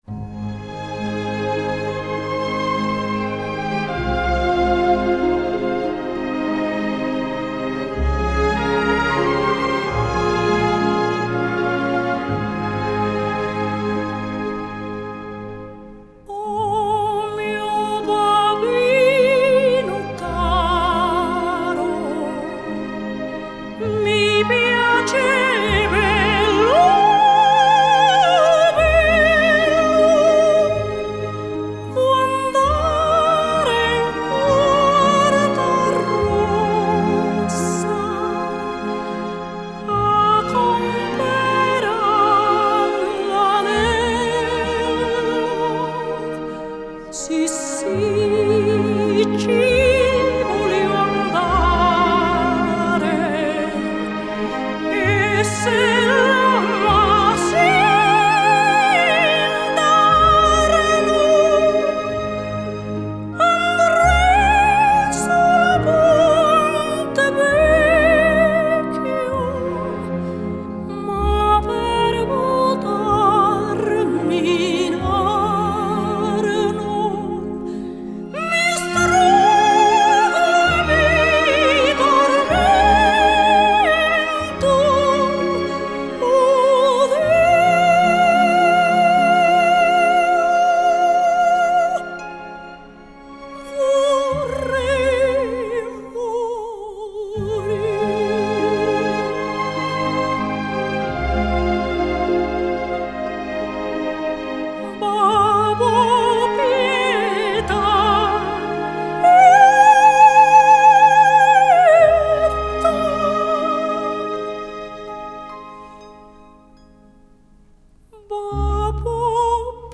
исполнителя популярных оперных арий